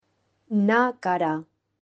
Audio file of the word "Naqqara"
Naqqara-Name.mp3